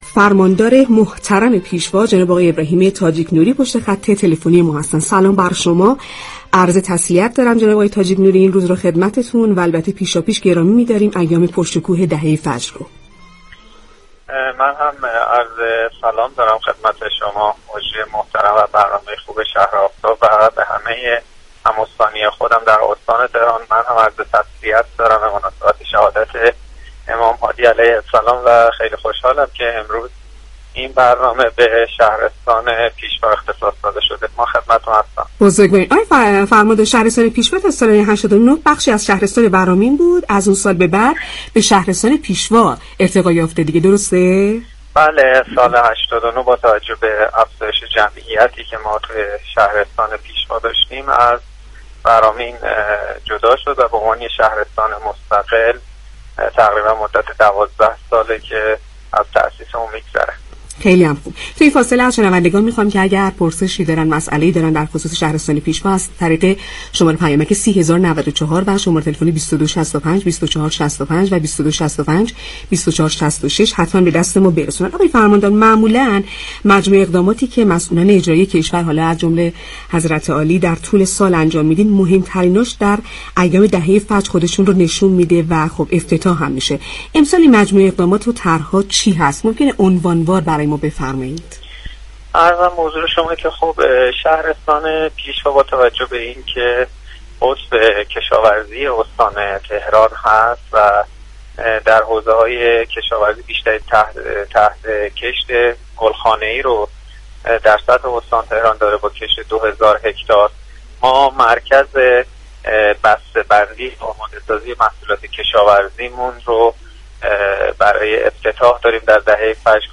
به گزارش پایگاه اطلاع رسانی رادیو تهران، ابراهیم تاجیك نوری فرماندار شهرستان پیشوا در گفت و گو با «شهر آفتاب» در پاسخ به این پرسش كه در ایام دهه فجر چه طرح‌هایی در شهرستان پیشوا به بهره‌برداری خواهد رسید؟